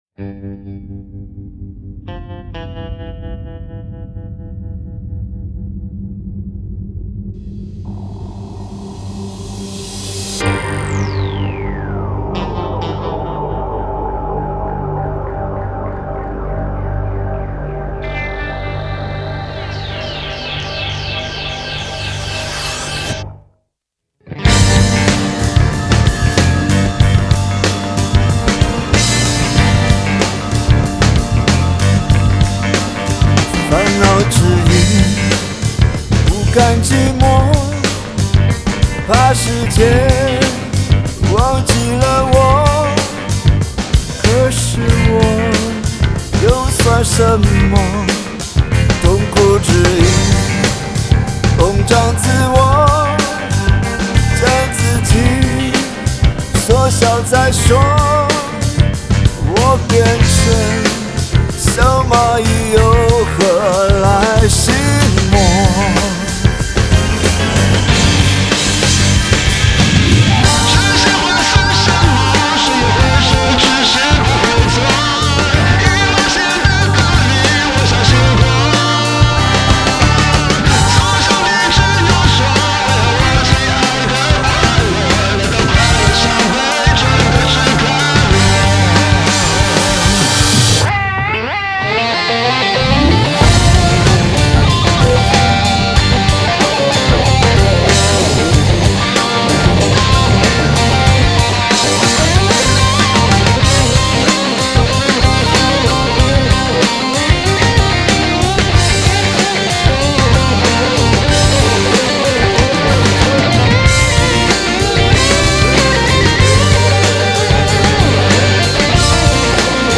Ps：在线试听为压缩音质节选，体验无损音质请下载完整版 作曲